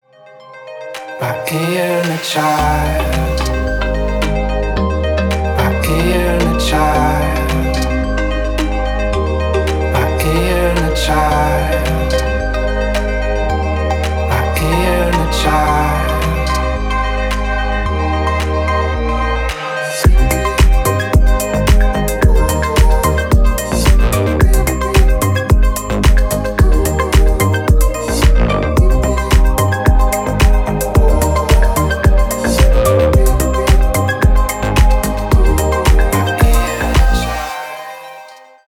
• Качество: 320, Stereo
мелодичные
dance
Electronic
EDM
house